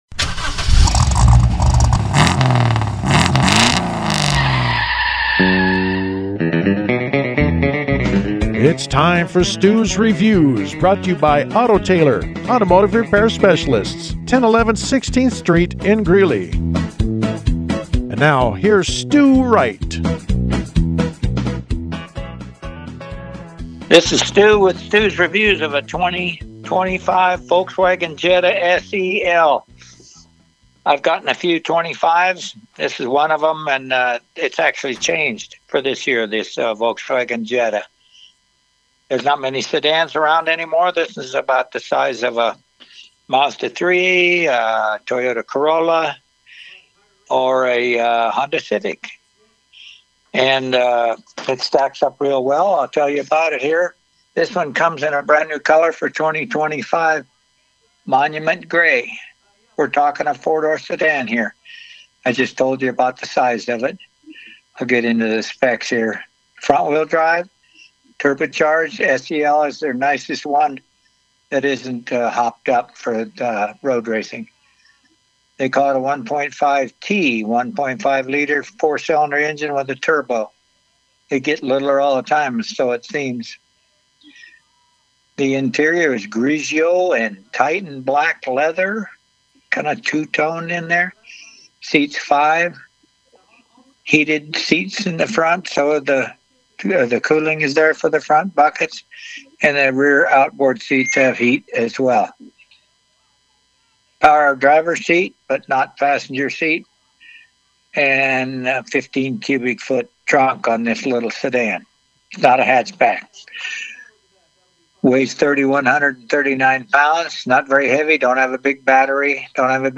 The Jetta review was broadcast on Pirate Radio 104.7FM in Greeley: